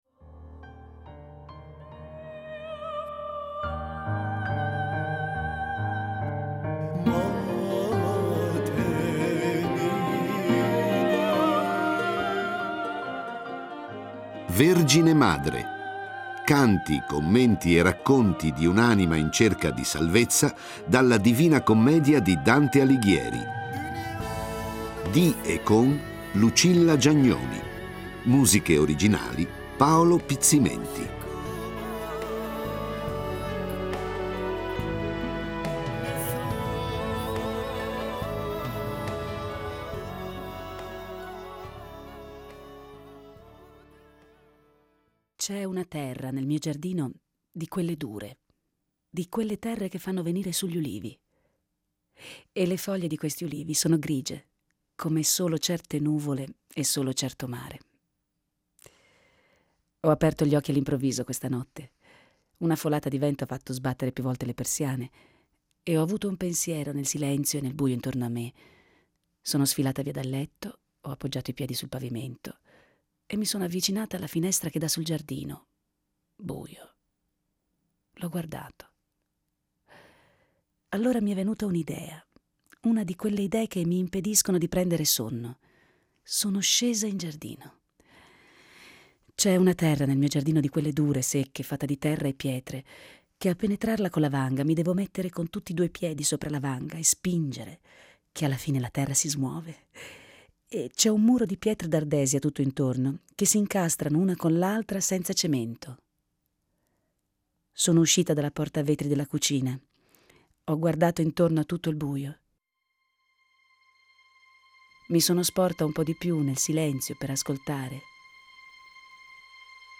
A cantare e raccontare storie è una donna.